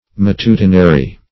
Matutinary \Ma*tu"ti*na*ry\, a.